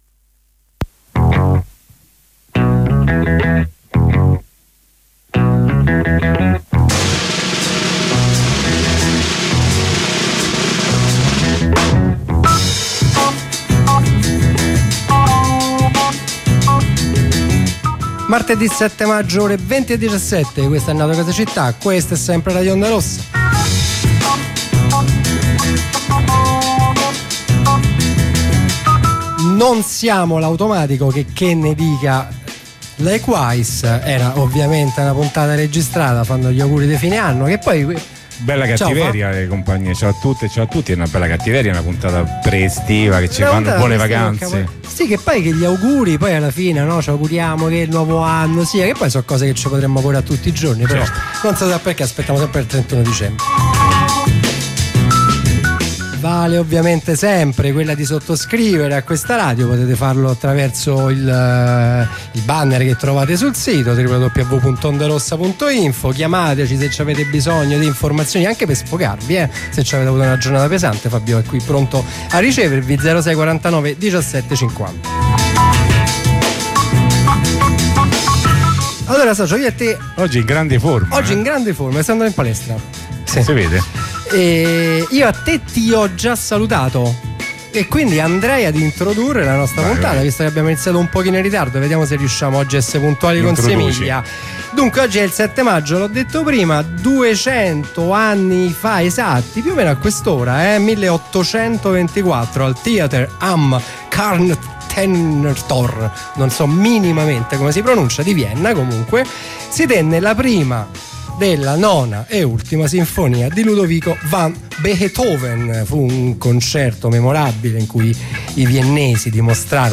Coro.ogg